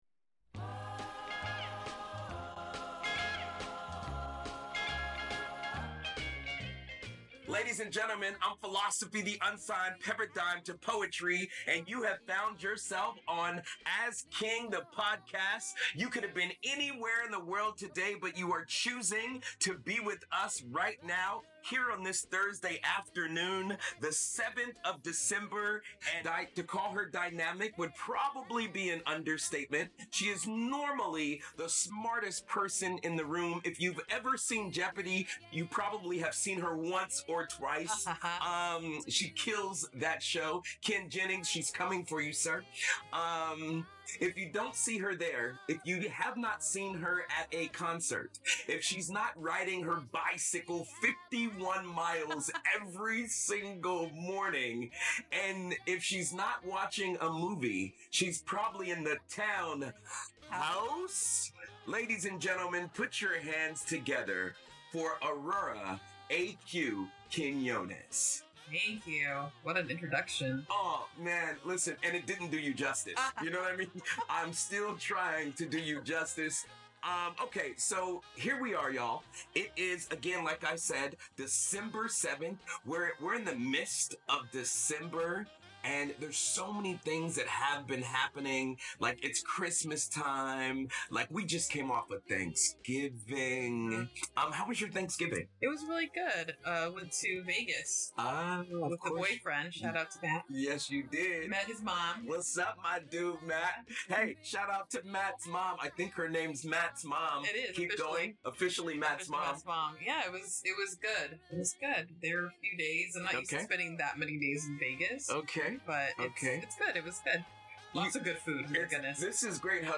Give it a listen for a warm and fuzzy feel! This was produced with our original studio set up. Note the old sound and reminisce about the early days of CityHeART Radio!